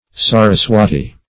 Meaning of saraswati. saraswati synonyms, pronunciation, spelling and more from Free Dictionary.
Search Result for " saraswati" : The Collaborative International Dictionary of English v.0.48: Saraswati \Sa`ras*wa"ti\, n. [Skr.